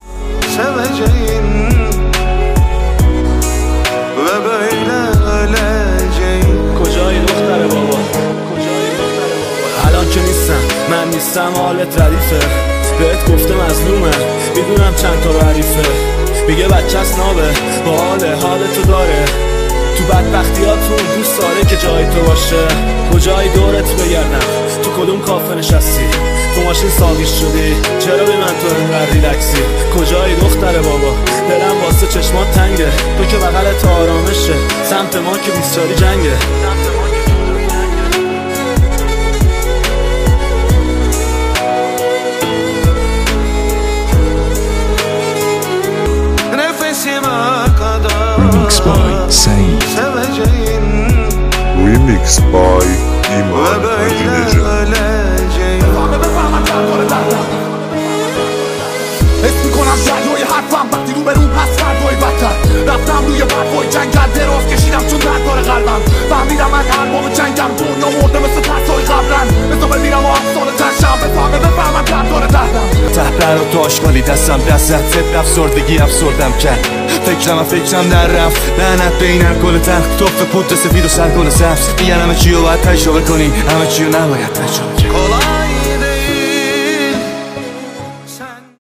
ریمیکس احساسی